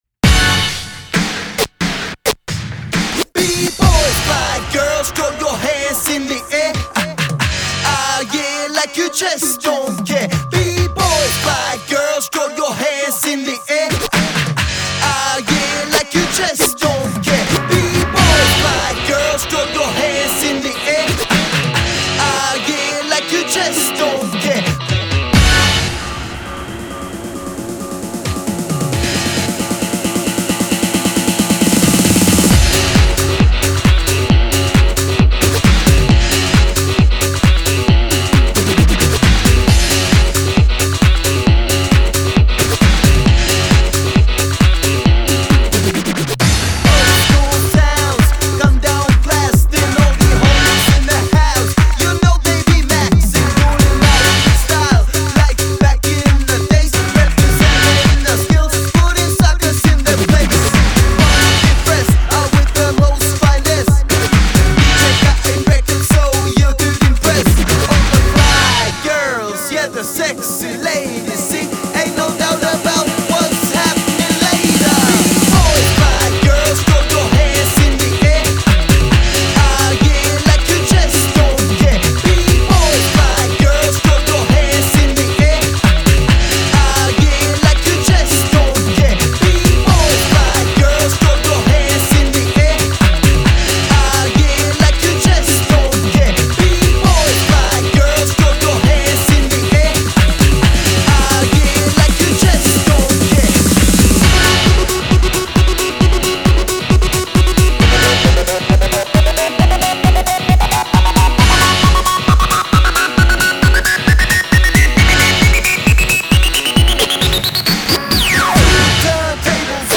Жанр: хип-хоп-реп